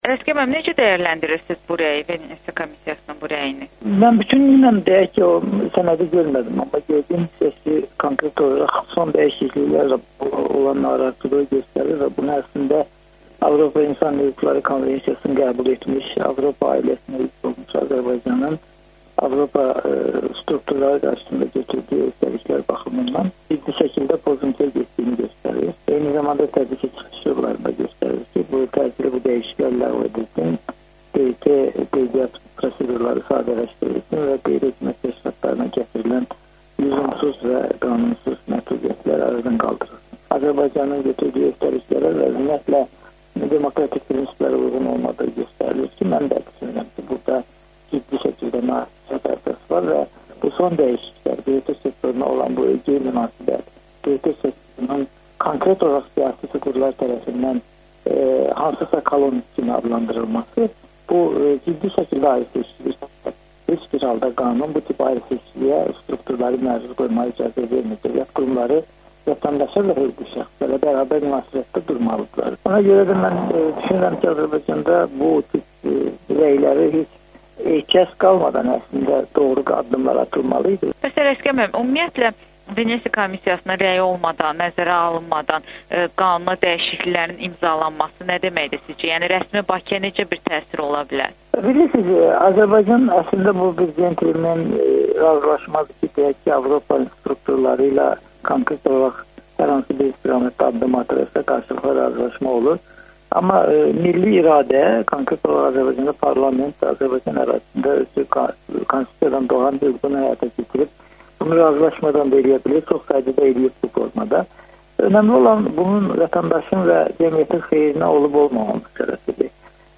müsahibəni təqdim edirik.